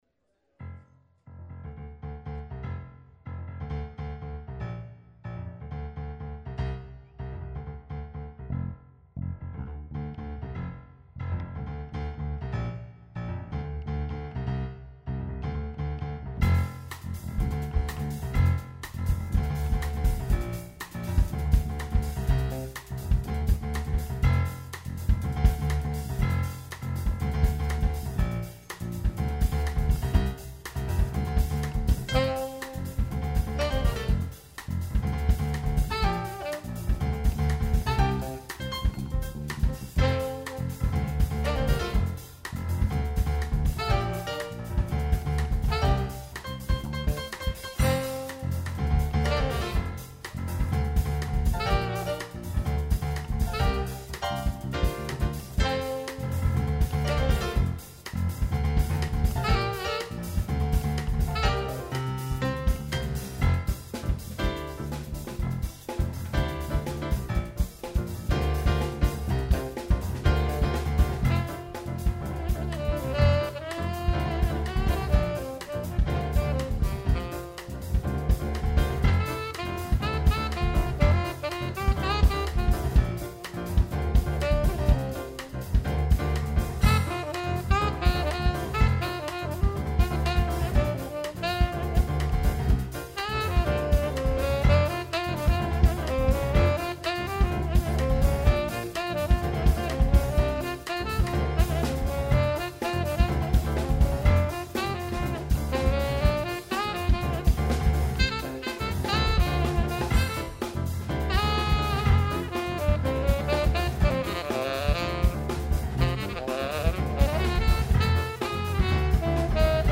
jazz tune
Type: Live Recording
piano
drums
bass
saxophone) Location: Konstanz